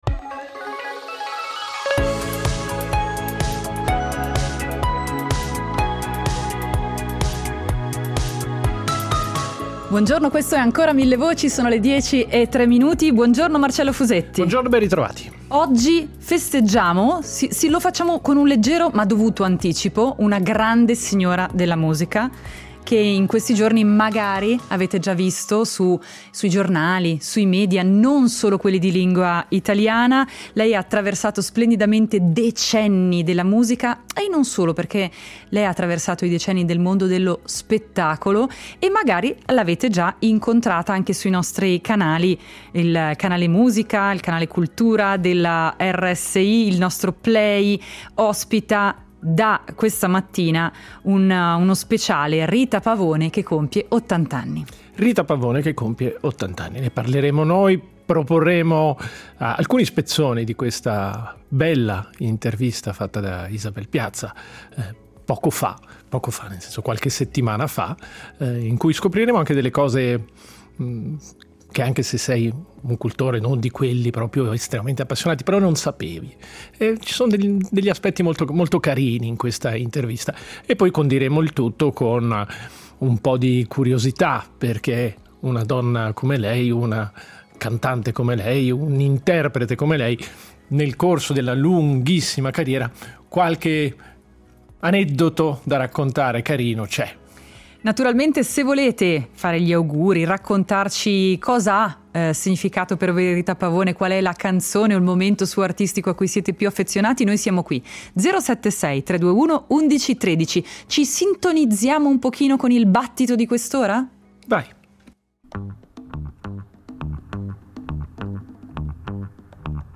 La cantante italiana si racconta alla RSI a ottant’anni dalla nascita